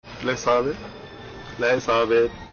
• Let’s have it (Comedy sound effect)
Lets-have-it-Comedy-sound-effect.mp3